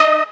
Mainlead_Melody24.ogg